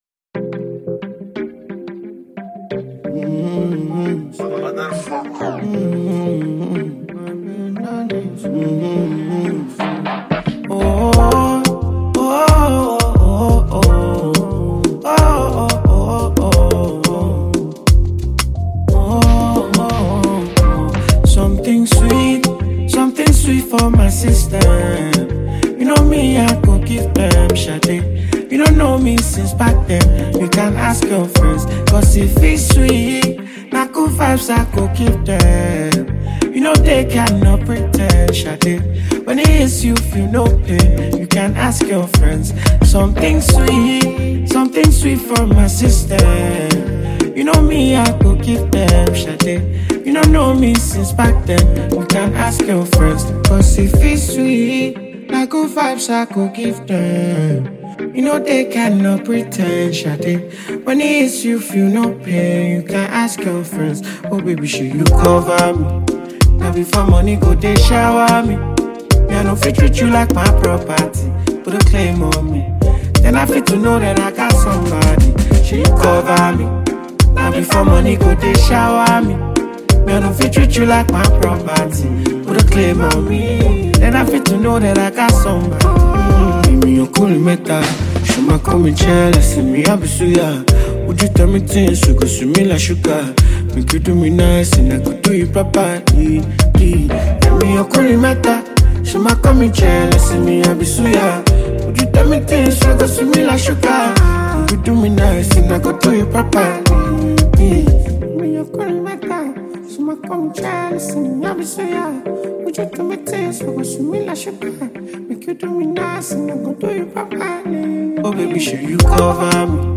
love song